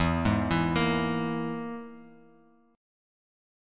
EMb5 chord